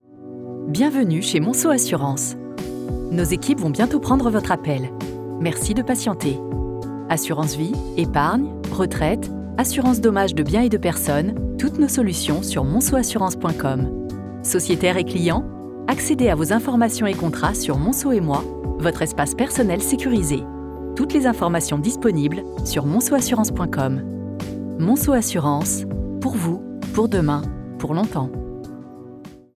Serveur vocal : Monceau assurances